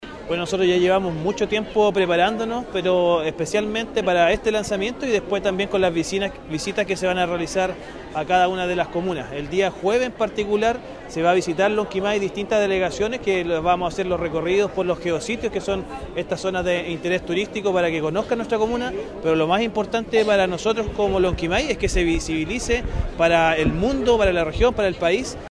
Con delegaciones de más de 50 países se inauguró en el Teatro Municipal de Temuco, región de La Araucanía, la undécima conferencia internacional de Geoparques de la Unesco, que se realiza por primera vez en Sudamérica.
Por su parte, el alcalde de Lonquimay, Eduardo Yánez, dijo que con esta iniciativa su comuna se abre al mundo.